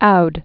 (oud)